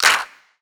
Clap MadFlavor 4.wav